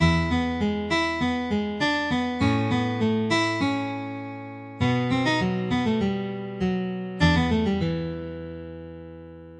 在西贝柳斯的E大调的四个酒吧吉他构成。琶音。和弦进展I IV V I.